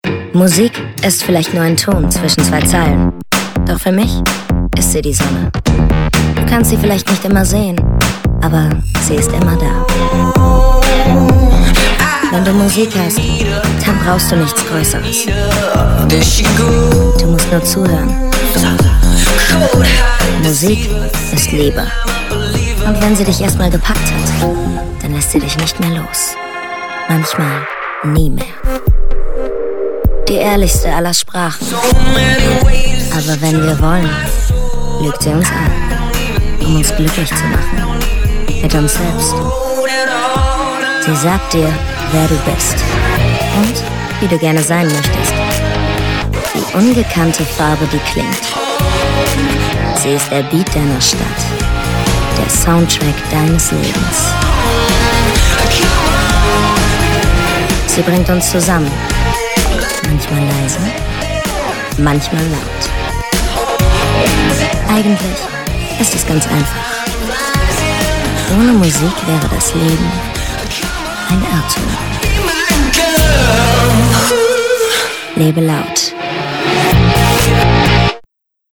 Junge & frische Werbesprecher:innen – New Voices
junge Werbesprecherin mit Profil
Stimmcharakter:         cool, frech, markant, eigenständig